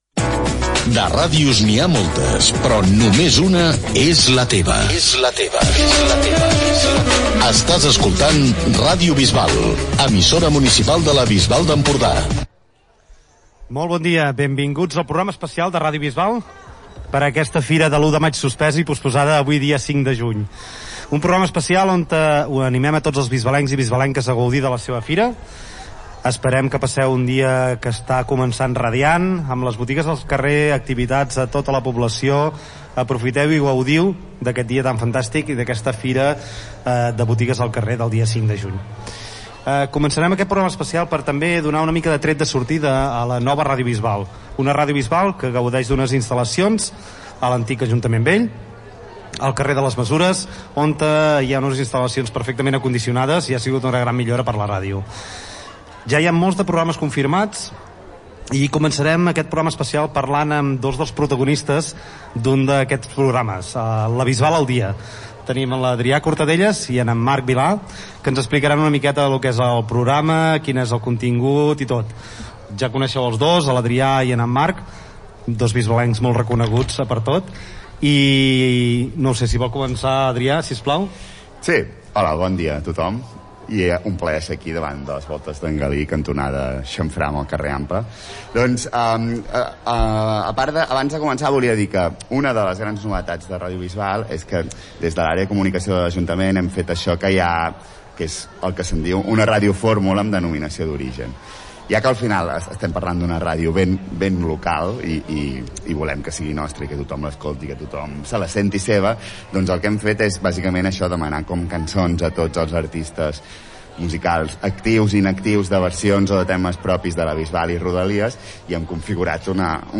Indicatiu de l'emissora, especial Fira de l'1 de maig (feta el mes de juny), comentari dels nous estudis i dels nou programes.
Informatiu